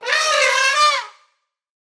めばえ４月号 ゾウのなきごえ